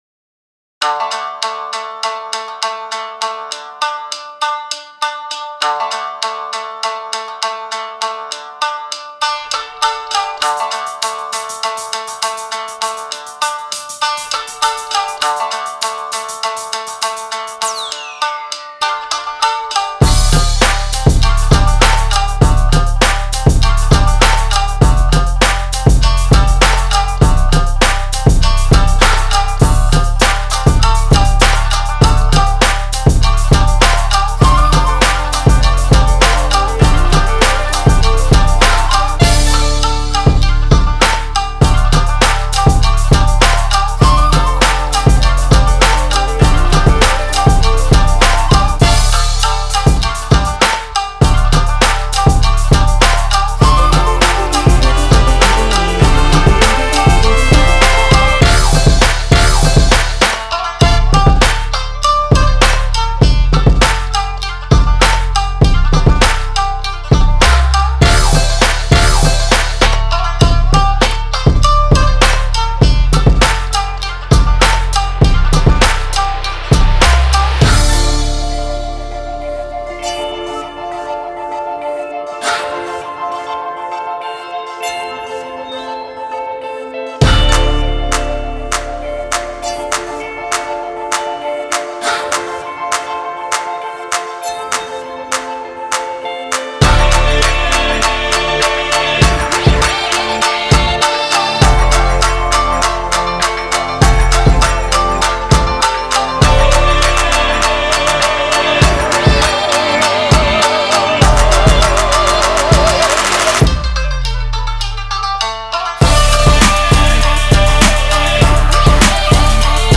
BPM100-100
Audio QualityPerfect (High Quality)
Full Length Song (not arcade length cut)